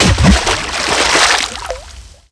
impactwaterlarge02.wav